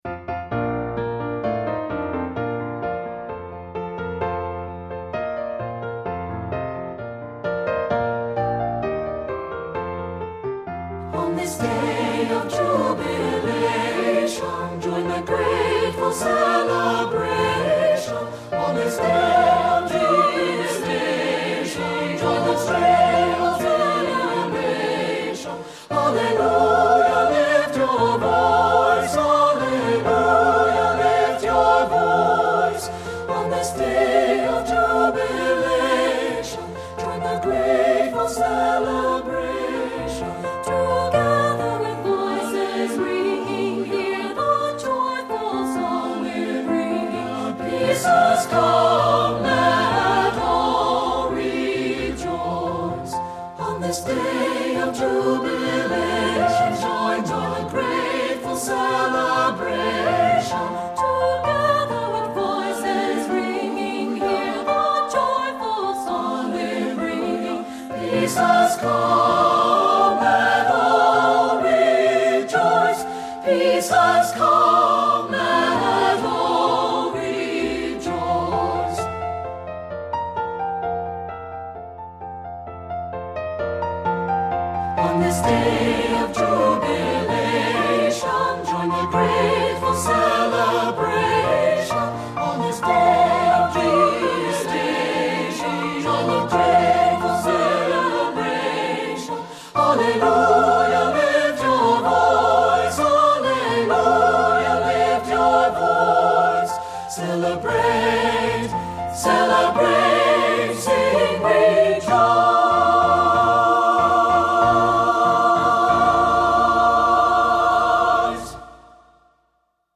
SAB a cappella Level